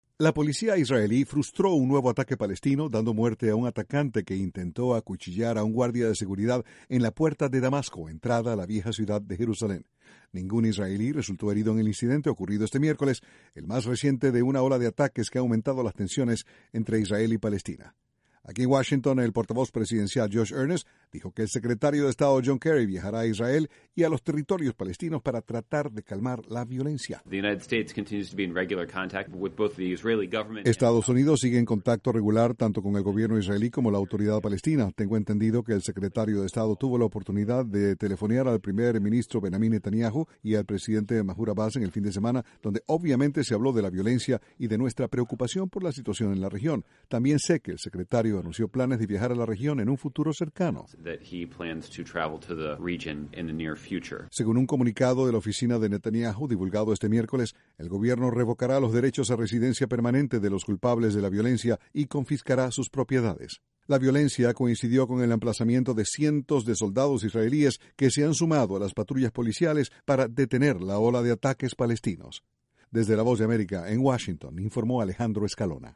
El Secretario de Estado John Kerry viajará al Medio Oriente para ayudar a que disminuyan las tensiones entre israelíes y palestinos. Desde la Voz de América, Washington